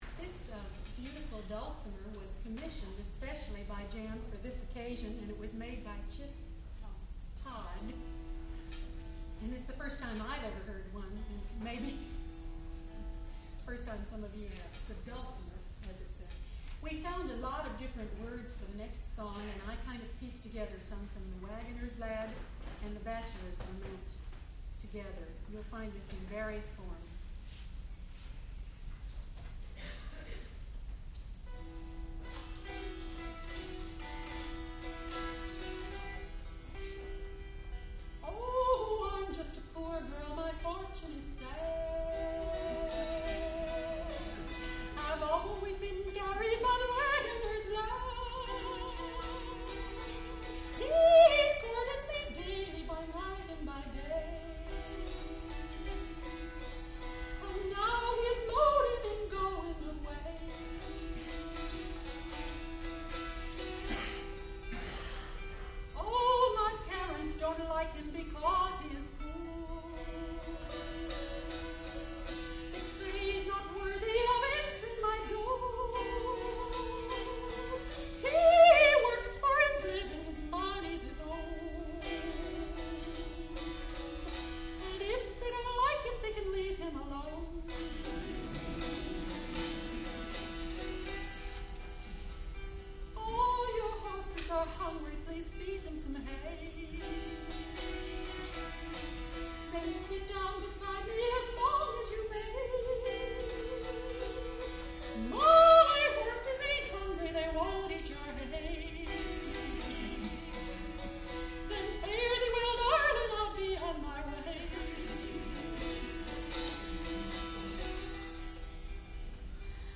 American folk songs
dulcimer, guitar and banjo
soprano